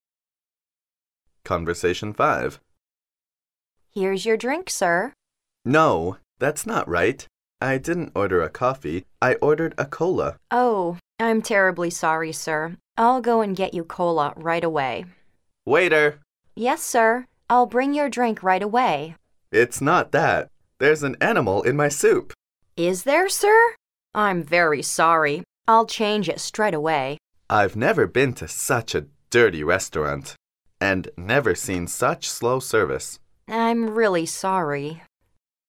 Conversation 5